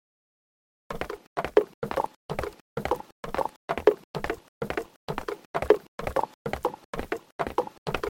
Cartoon Horse Bouton sonore